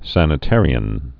(sănĭ-târē-ən)